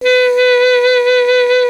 55AF-SAX08-B.wav